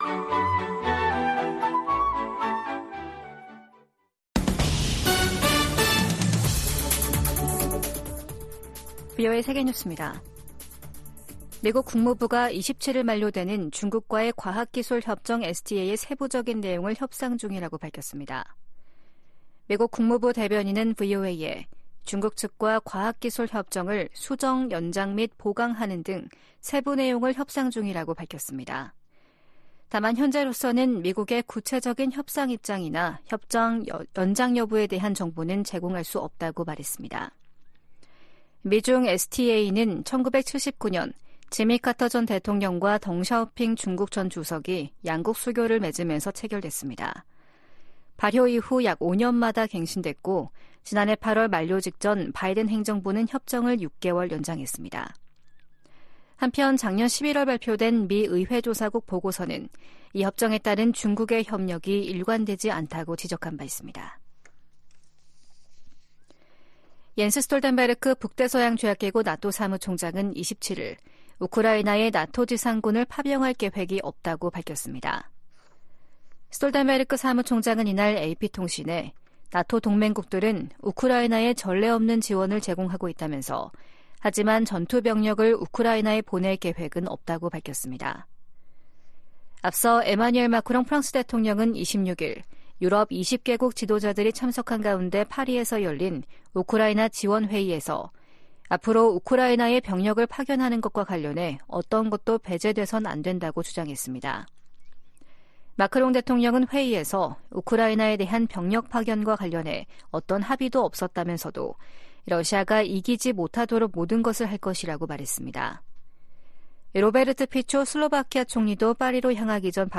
VOA 한국어 아침 뉴스 프로그램 '워싱턴 뉴스 광장' 2024년 2월 28일 방송입니다. 제네바 유엔 군축회의 첫날 주요국들이 한목소리로 북한의 핵과 미사일 개발을 강력히 비판했습니다.